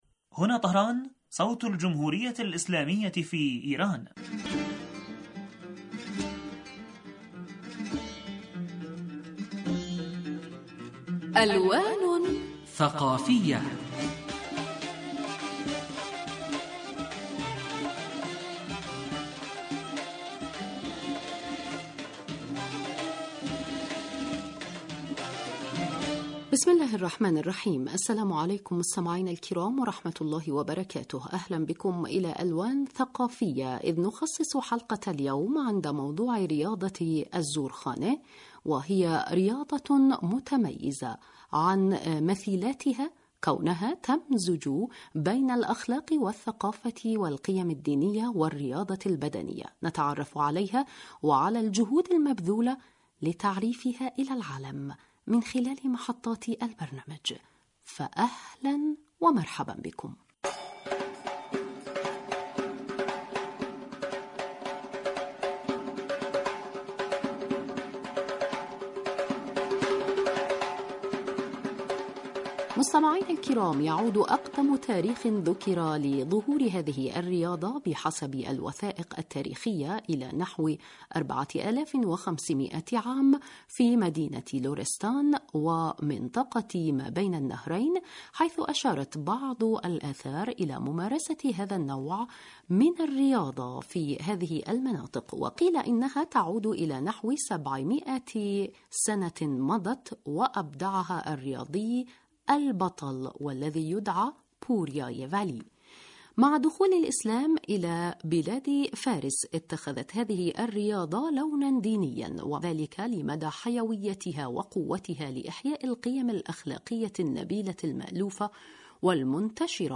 لقاء ثقافي وأدبي يجمعنا بكم أسبوعيا عبر تجوال ممتع في أروقة الحقول الثقافية والحضارية والأدبية لإيران الإسلامية ويشاركنا فيه عدد من الخبراء وذووي الاختصاص في الشأن الثقافي الإيراني لإيضاح معالم الفن والأدب والثقافة والحضارة في إيران.